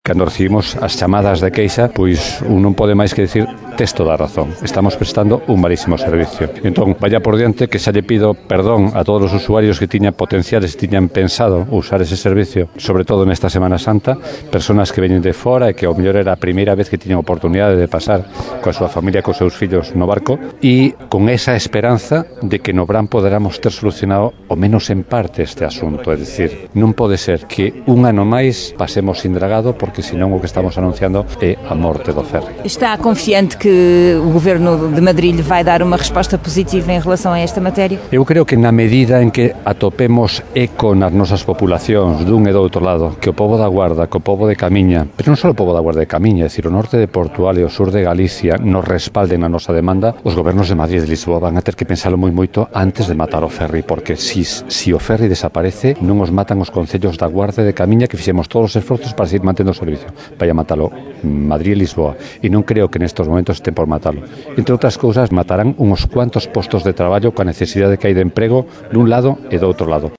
O Alcaide de La Guardia, José Manuel Freitas, explica que aquele município galego não pode, por enquanto, pagar qualquer dívida porque a autarquia está sem tesoureiro e, por isso, não pode fazer pagamentos. O autarca reconhece que o ferry está a funcionar com grandes dificuldades e pede, por isso, desculpas antecipadas a quem vier passar a Semana Santa a Caminha e La Guardia, que fica sem ligação directa.